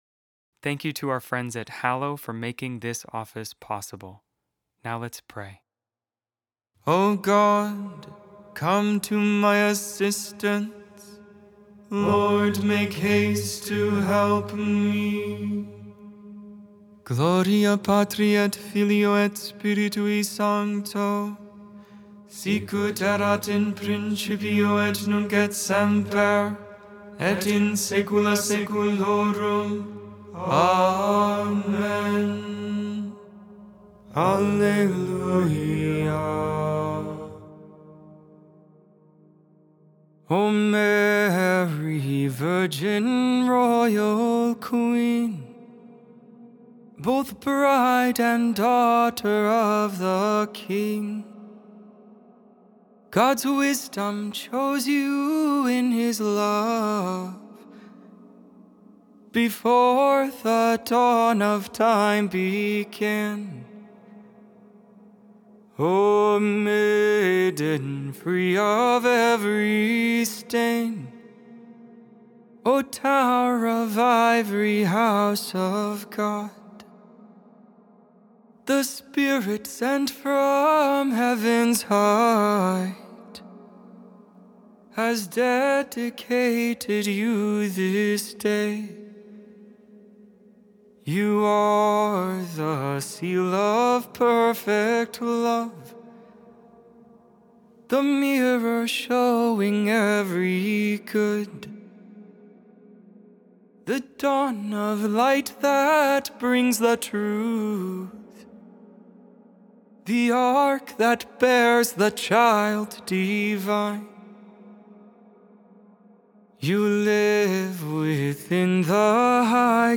Lauds, Morning Prayer for the 33rd, Friday in Ordinary Time, November 21, 2025.Memorial of the Presentation of the Blessed Virgin Mary.Made without AI. 100% human vocals, 100% real prayer.